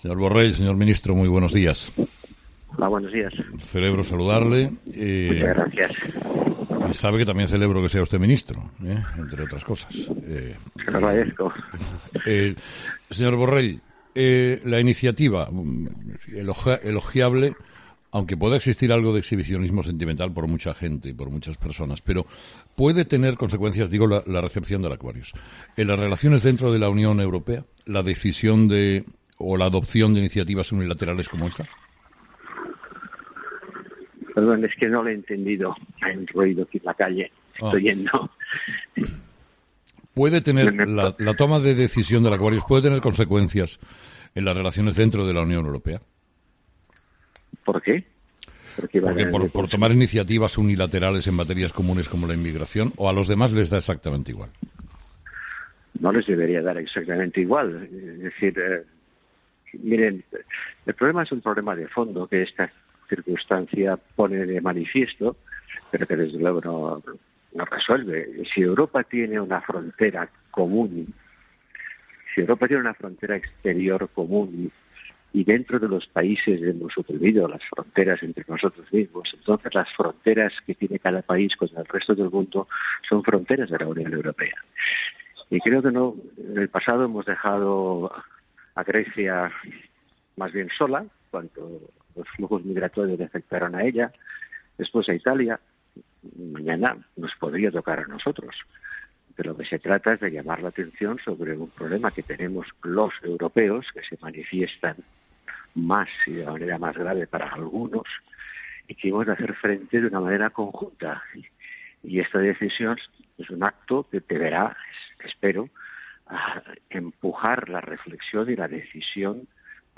El ministro de Asuntos Exteriores Josep Borrell ha explicado este miércoles en 'Herrera en COPE' que la decisión unilateral de que el buque Aquarius, con mas de 600 inmigrantes a bordo, atraque en aguas españolas no tiene por qué tener "consecuencias dentro" de la UE pero también apunta que a los miembros de la unión “no les debería dar igual” lo que está ocurriendo.